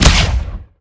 fire_neutron1.wav